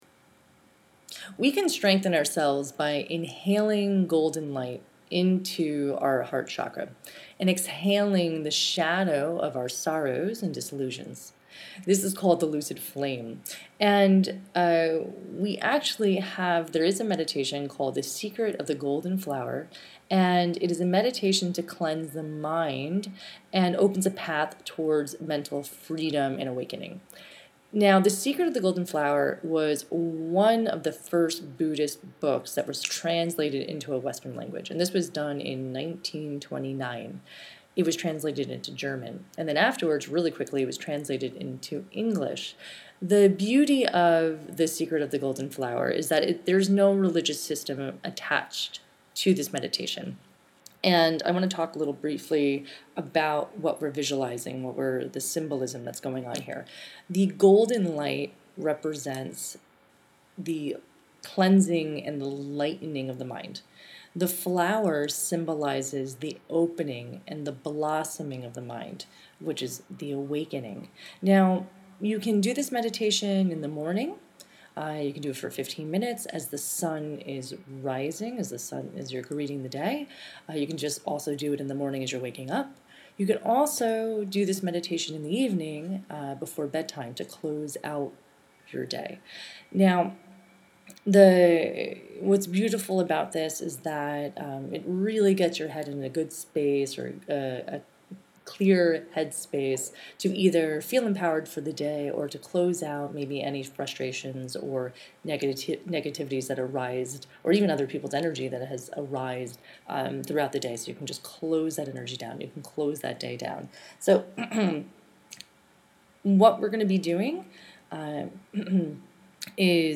Suggestion: Work with this guided meditation for a few weeks to allow for an internal shift.